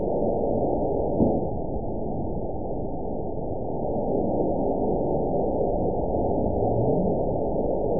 event 917215 date 03/24/23 time 11:34:33 GMT (2 years, 1 month ago) score 9.32 location TSS-AB04 detected by nrw target species NRW annotations +NRW Spectrogram: Frequency (kHz) vs. Time (s) audio not available .wav